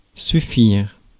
Ääntäminen
IPA: [sy.fiʁ]